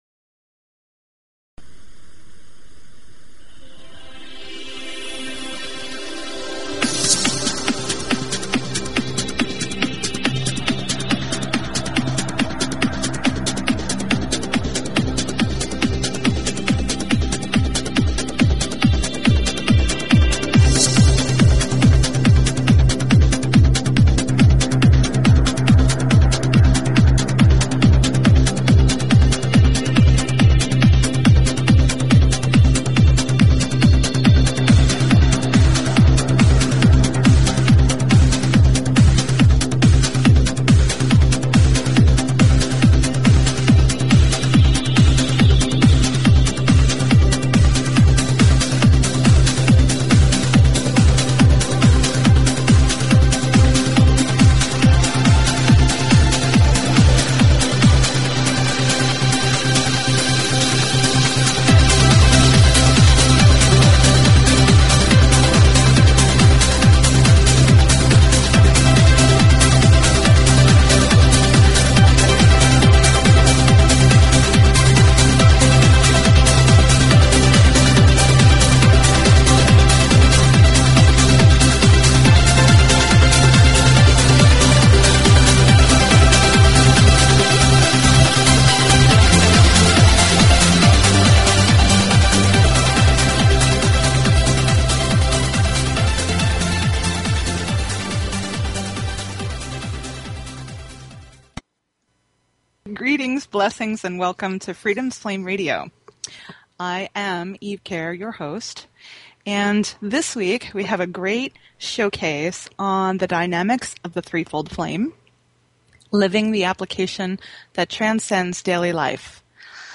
Talk Show Episode, Audio Podcast, Freedoms_Flame_Radio and Courtesy of BBS Radio on , show guests , about , categorized as